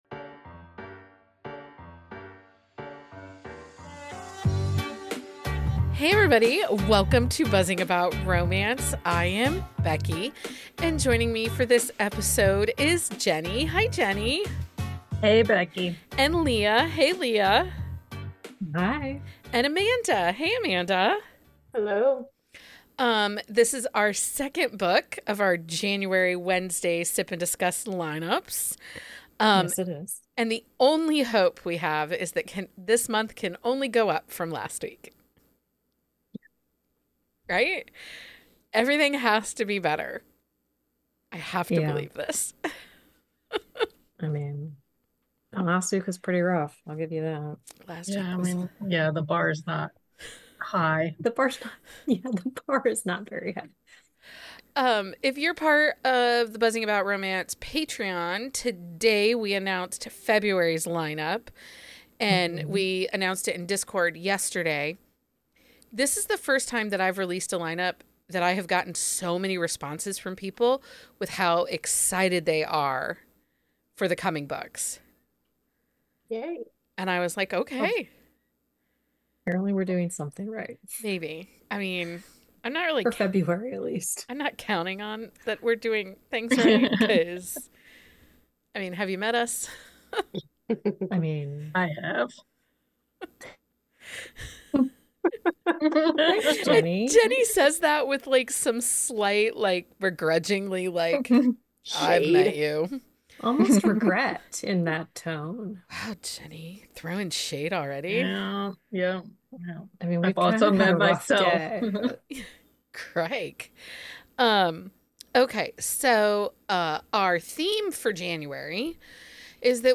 They chat about their favorite books, tropes, authors and book boyfriends. The hosts also bring you an inside look to Publishing of Romance via Author and Industry Insiders interviews.